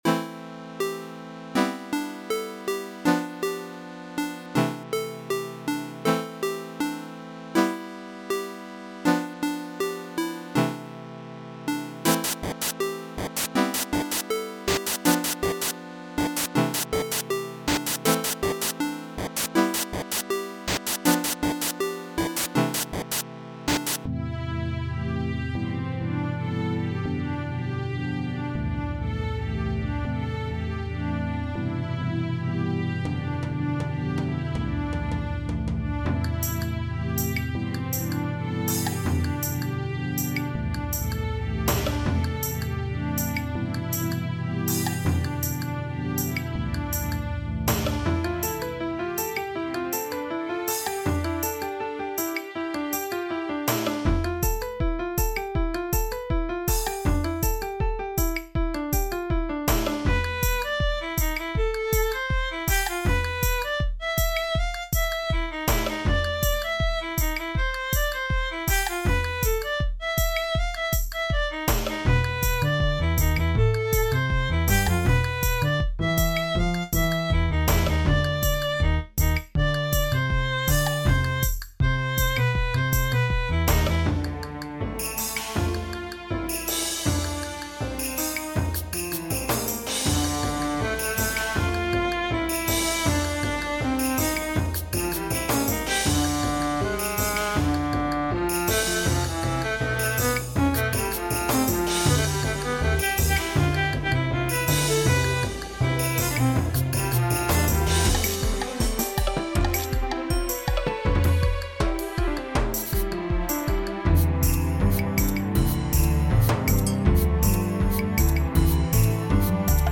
Music / Game Music